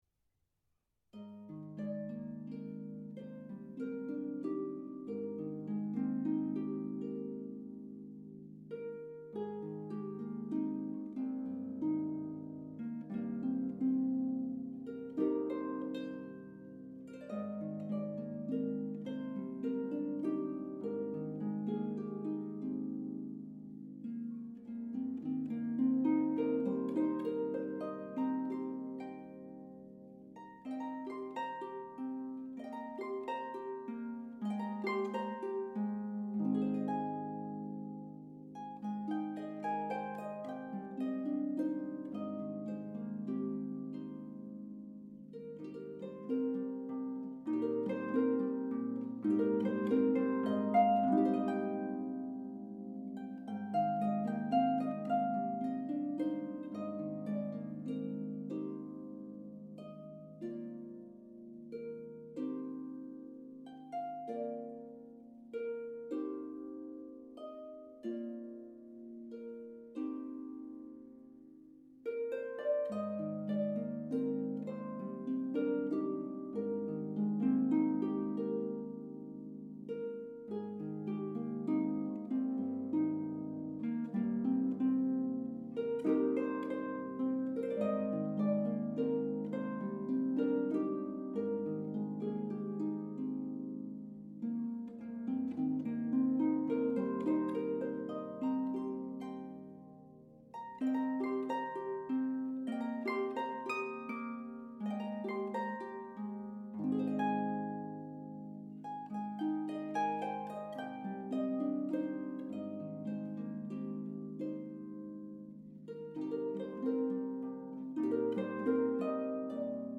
for solo pedal harp